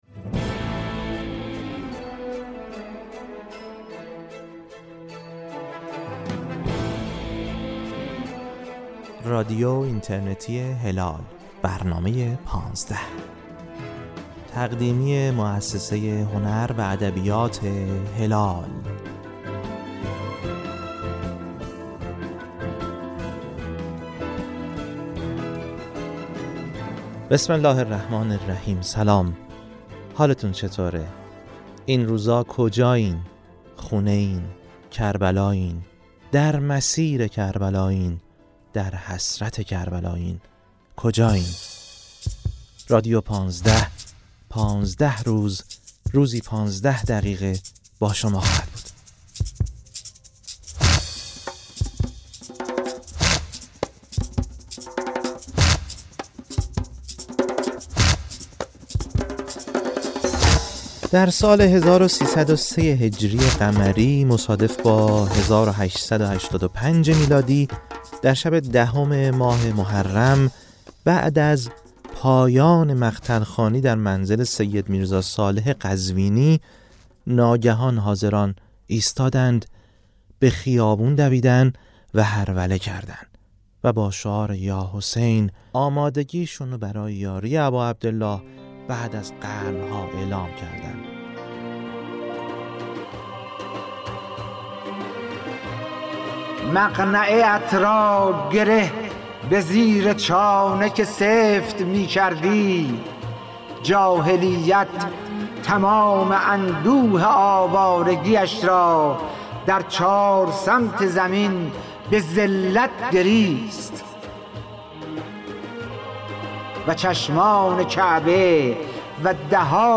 «پانزده» نام مجموعه‌ای‌ست شنیدنی از رادیو اینترنتی هلال؛ روایتی صوتی از شور و شعور حسینی. در این مجموعه‌ ۱۵ قسمتی، که از دل ارادت و اشتیاق ساخته شده، ترکیبی گرم و دل‌نشین از مداحی‌های ناب، بریده‌هایی از سخنرانی‌های تأثیرگذار، کتاب‌خوانی با صدای اساتید برجسته و نگاهی به فضیلت‌ها و پیام‌های اربعین ارائه می‌شود؛ فرصتی برای آنان که در مسیرند و آنان که دل‌شان در مسیر است.